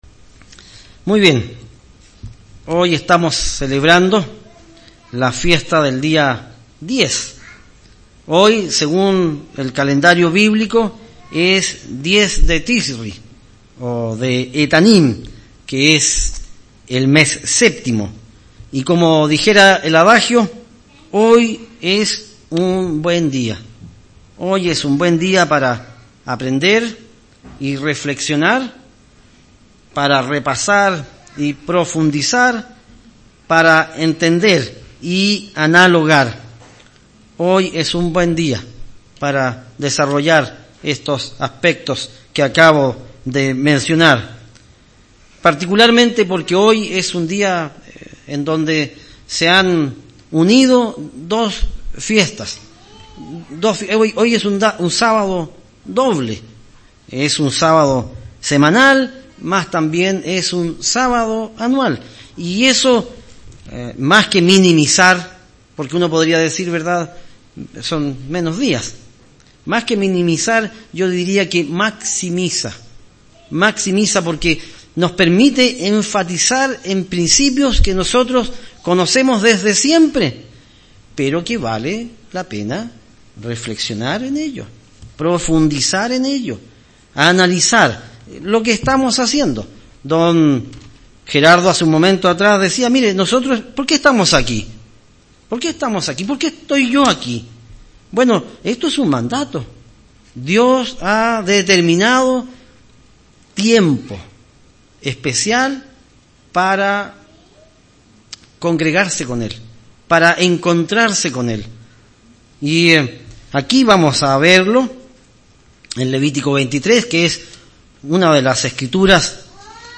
Las Fiestas Solemnes del Eterno son oportunidades maravillosas para reflexionar y madurar. Mensaje entregado 30 de septiembre de 2017